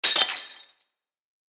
お皿が割れる音響きあり3.mp3